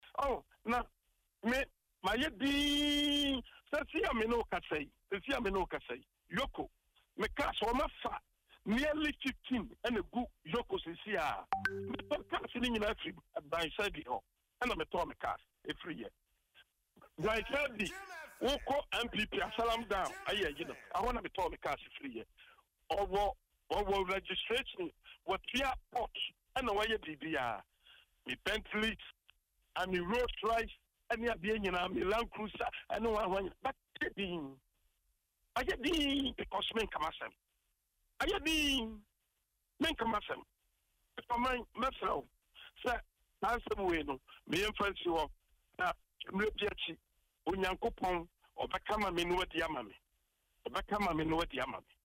Speaking on Adom FM’s Dwaso Nsem morning show, Wontumi revealed that the seized vehicles include luxury brands such as a Bentley and a Rolls Royce.